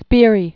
(spîrē, shpîrē), Johanna Heusser 1827?-1901.